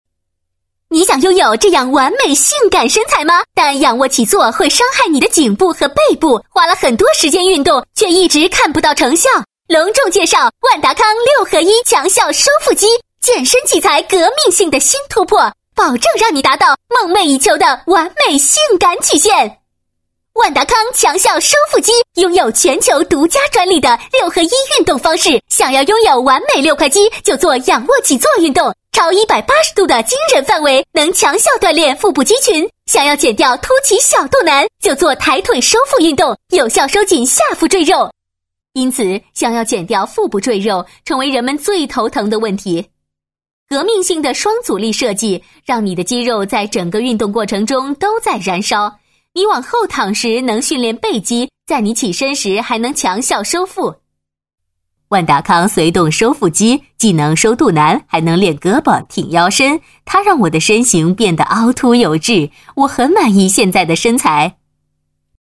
女国112_广告_电购_角色万达康.mp3